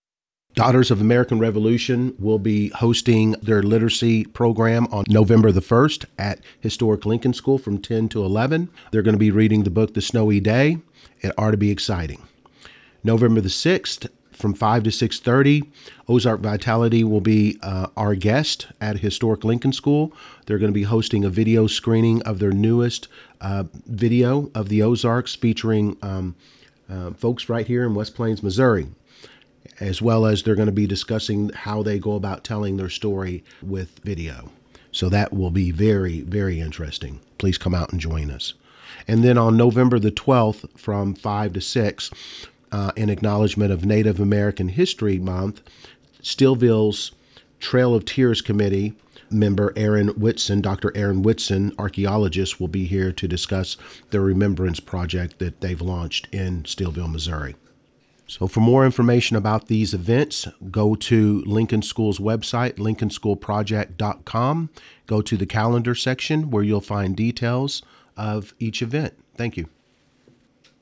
a brief interview